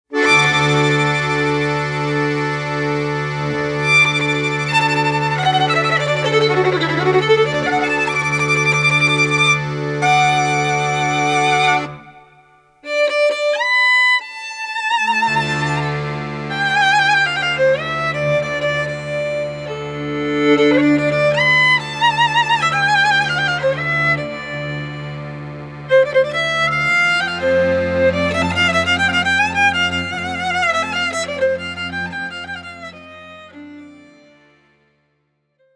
Violin
C Clarinet
Accordions, Tsimbl
Bass Cello
Genres: Klezmer, Polish Folk, Folk.